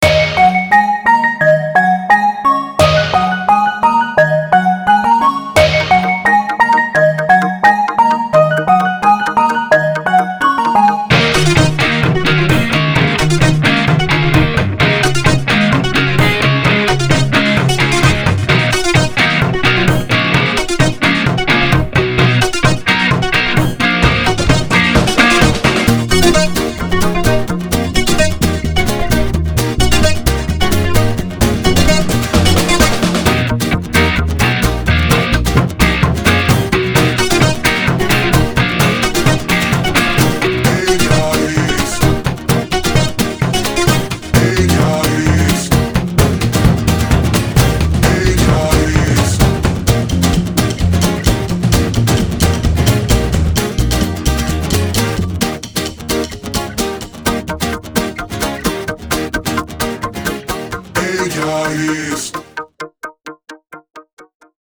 Produção sonora vinheteira, com notas distintas e com ênfase na redundância cíclica.